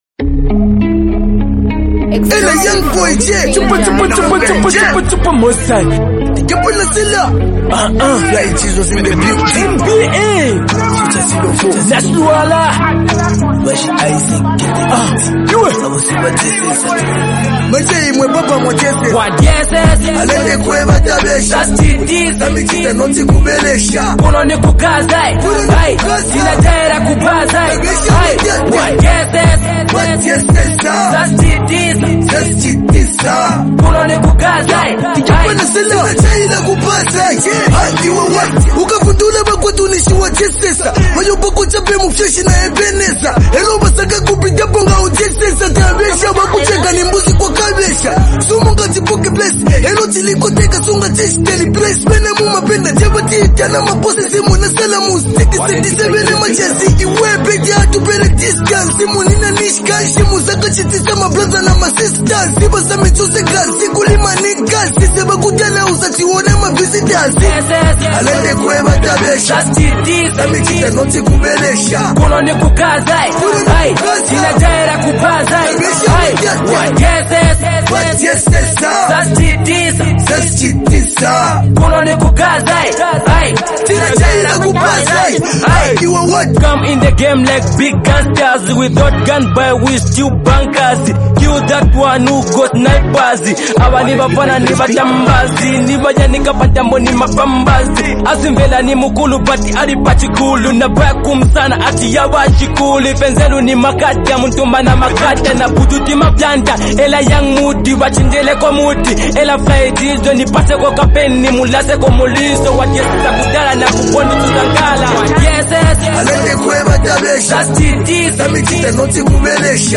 an emotionally driven song